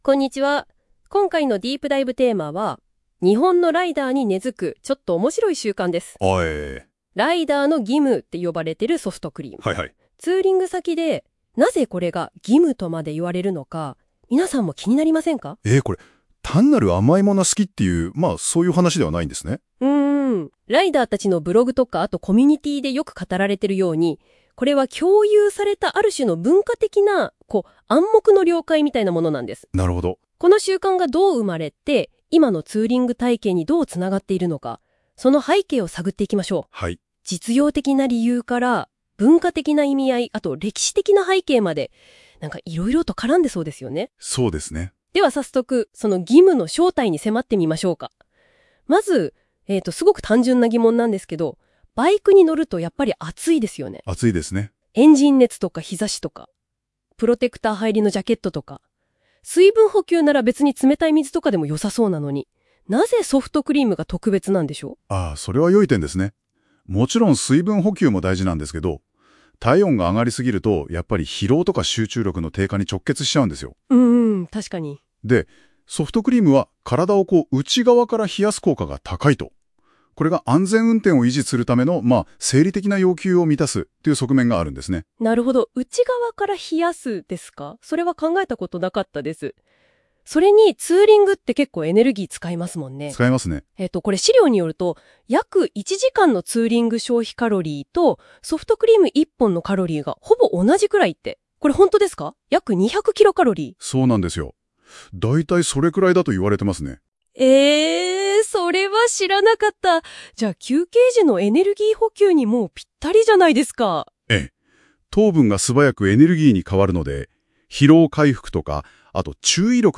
↓AIでこの記事に関連した音声を作成してみました